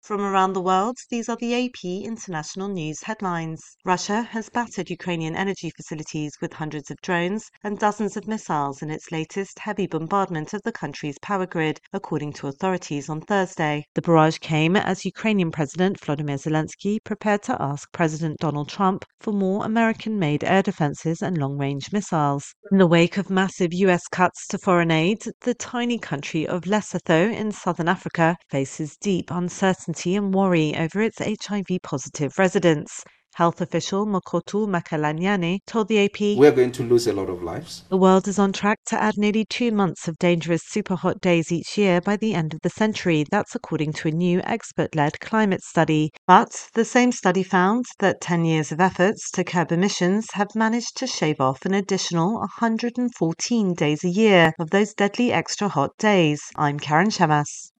The latest international news headlines